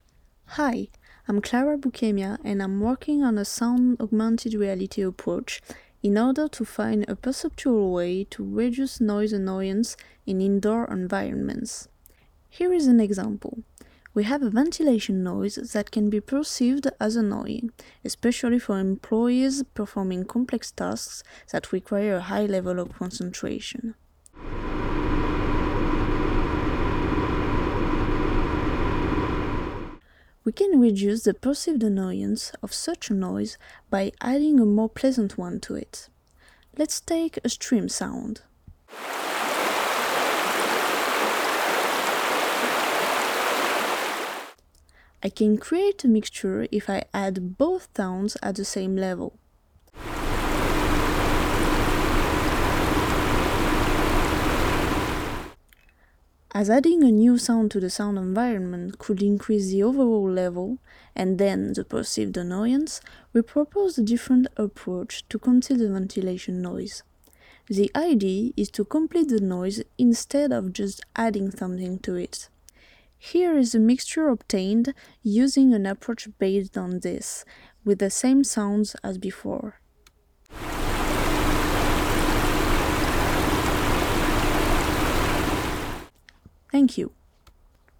It presents the ReNAR project (Reducing Noise with Augmented Reality), with some sounds generated using sound augmented reality approaches.